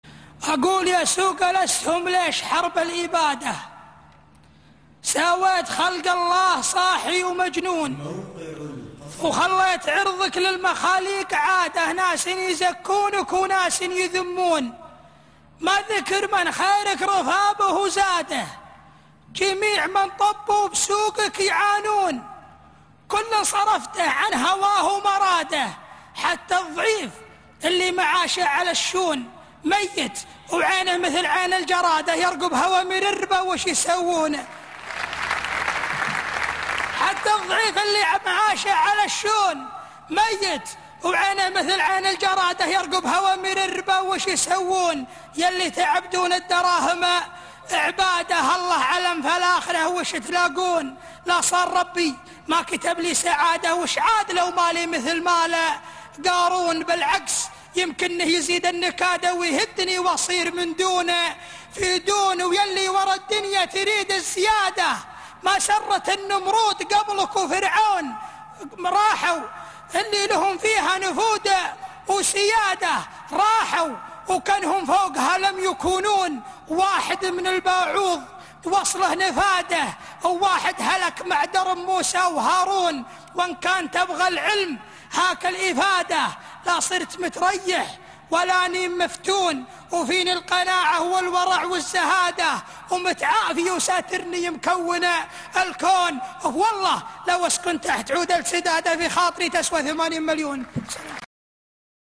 الاسهم - امسيات دبي   17 مارس 2012